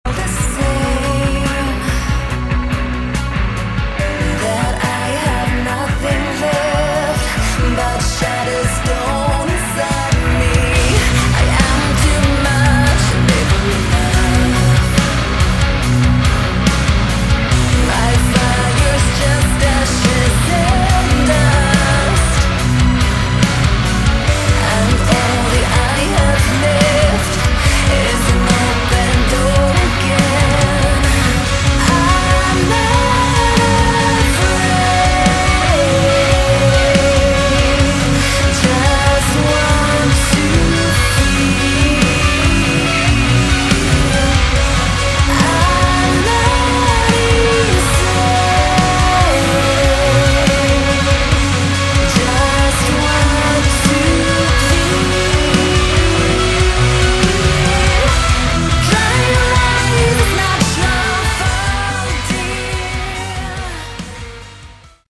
Category: Melodic Metal
vocals
guitars
keyboards
bass
drums